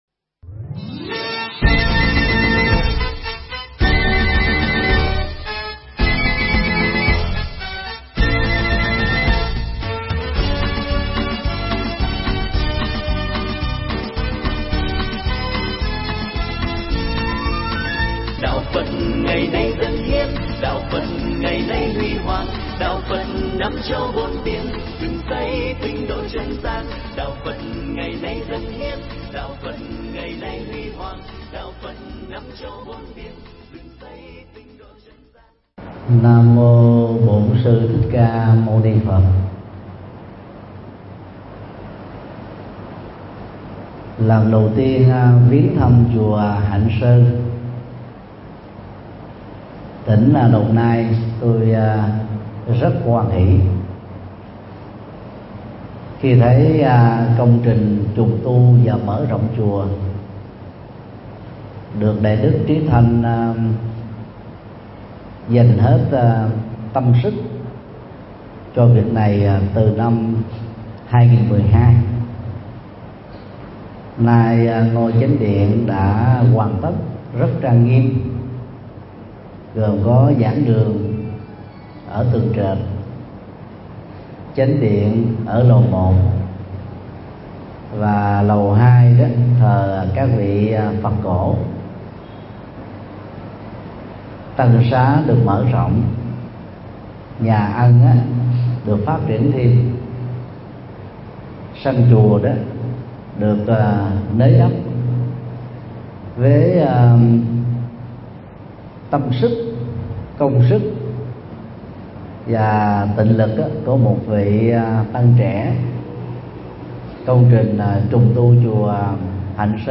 Mp3 Pháp Thoại Đừng Để Quá Muộn Màng – Thượng Tọa Thích Nhật Từ giảng tại chùa Hạnh Sơn (Đồng Nai), ngày 23 tháng 4 năm 2017